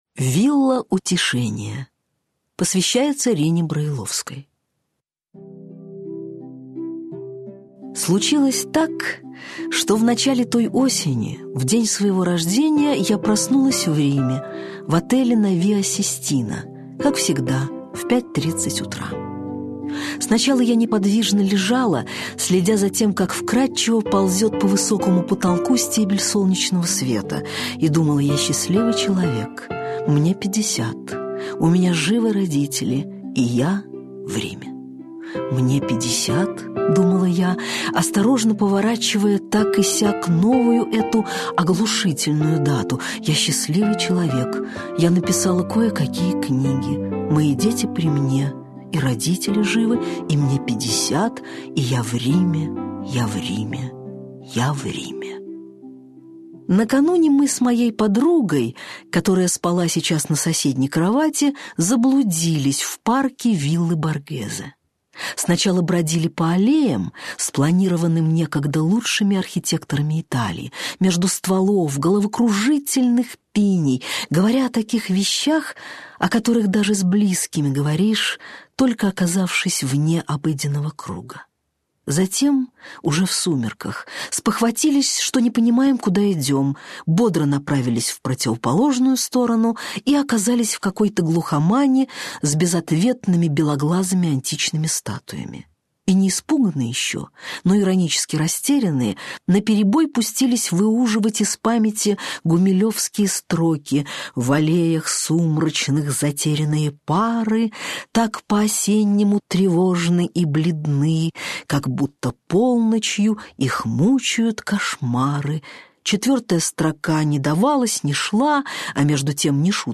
Аудиокнига Вилла «Утешение» | Библиотека аудиокниг
Aудиокнига Вилла «Утешение» Автор Дина Рубина Читает аудиокнигу Дина Рубина.